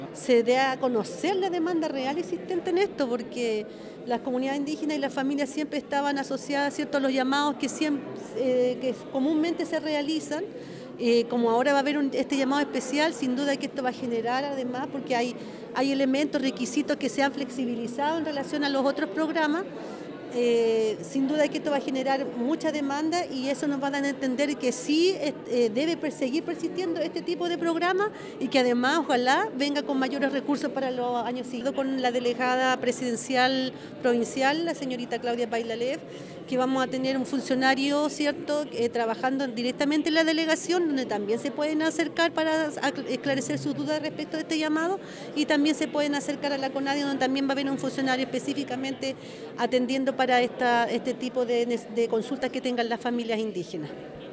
Por su parte la Directora Regional de la Corporación Nacional de Desarrollo Indígena, Marcela Urbano destacó la tenacidad de los dirigentes de los distintos territorios, para lograr avanzar en que las políticas públicas incluyan las necesidades de los sectores más apartados.